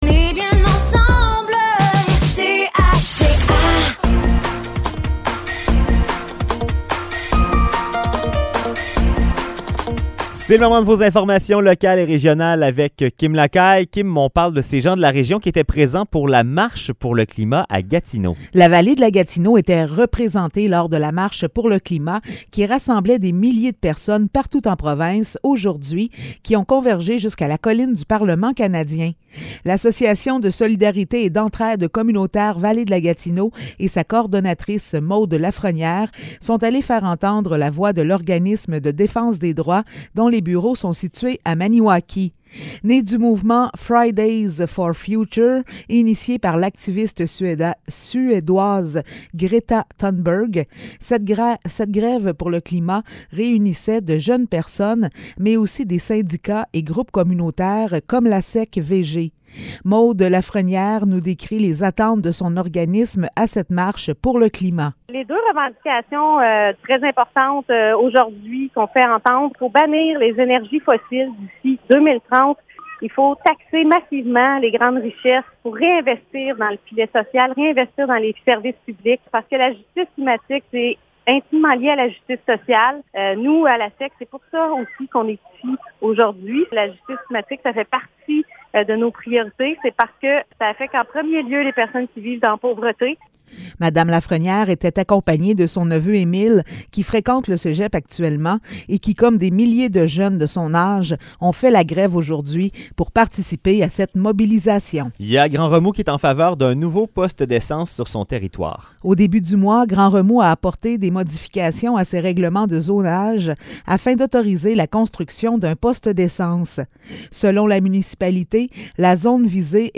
Nouvelles locales - 23 septembre 2022 - 16 h